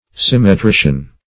Symmetrician \Sym`me*tri"cian\, n.